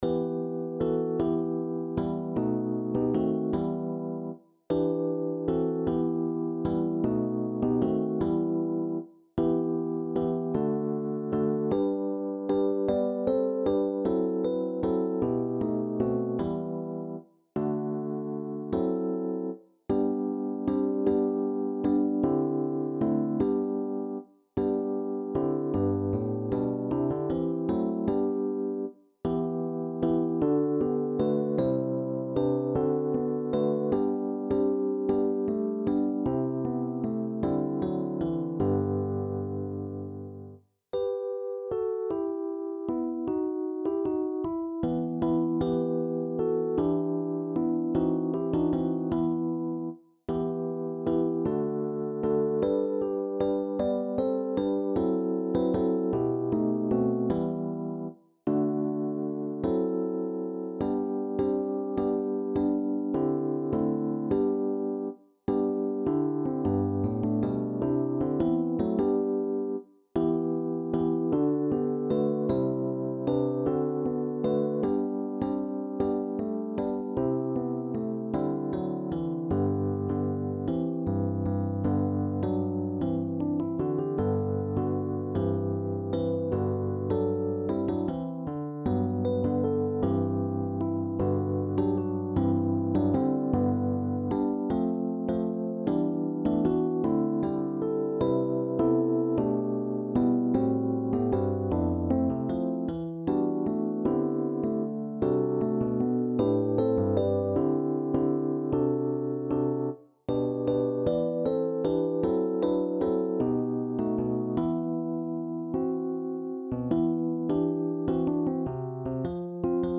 SATB (div.)